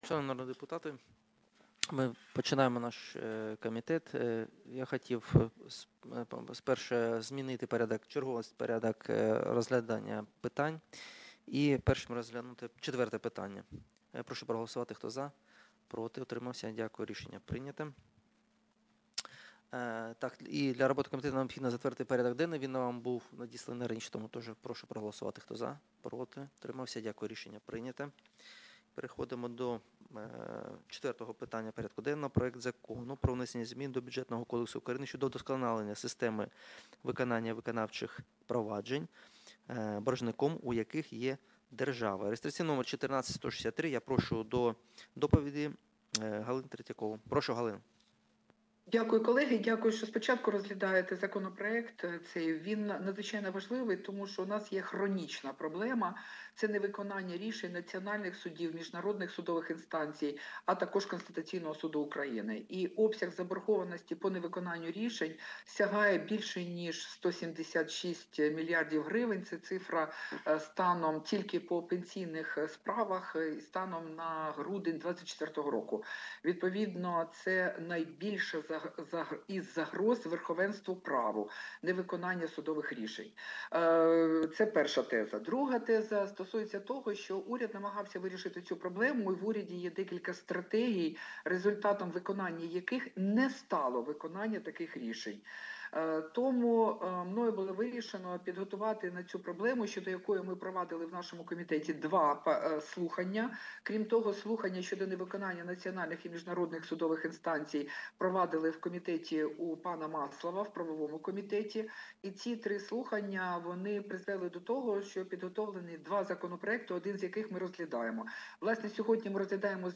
Аудіозапис засідання Комітету від 16.12.2025